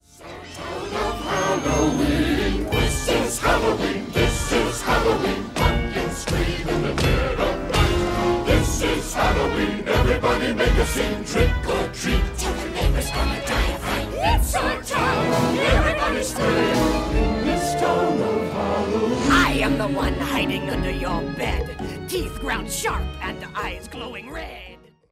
nous plonge dans l’ambiance mystérieuse de la fête